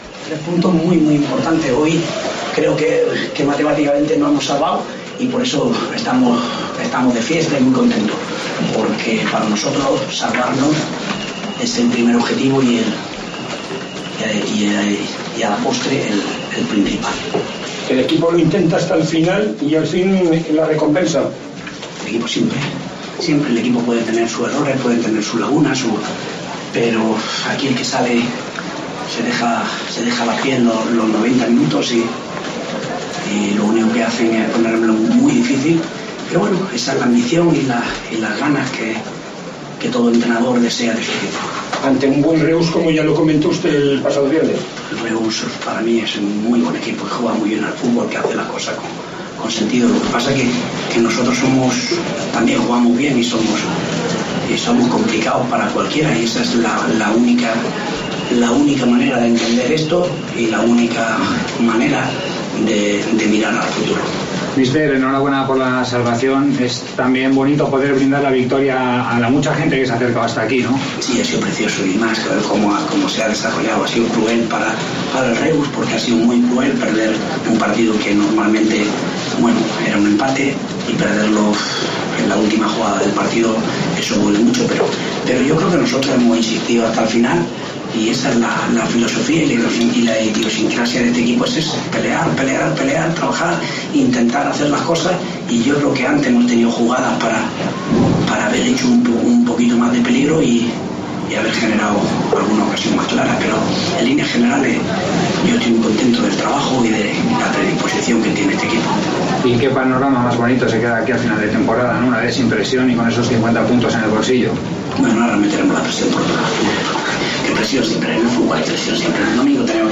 Declaraciones del entrenador de la Sociedad Deportiva Huesca después de ganar (0-1) en Reus.
Anquela en la sala de prensa de Reus